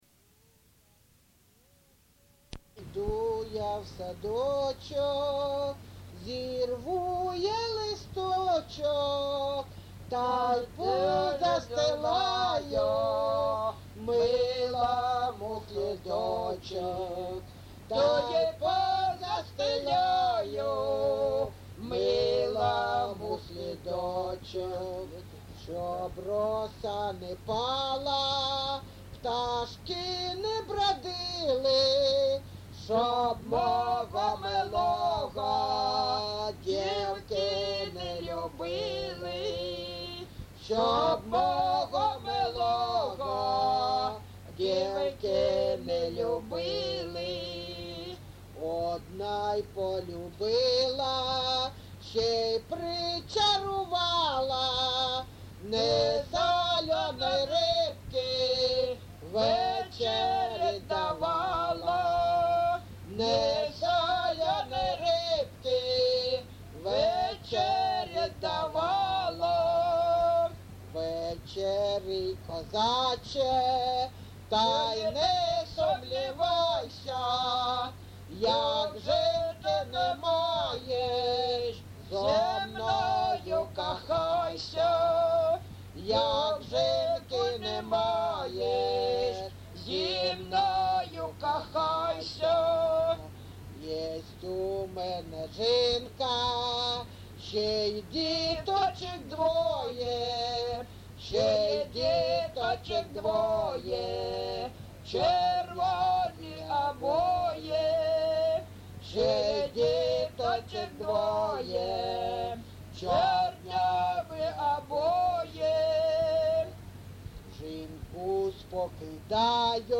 ЖанрПісні з особистого та родинного життя
Місце записус. Григорівка, Артемівський (Бахмутський) район, Донецька обл., Україна, Слобожанщина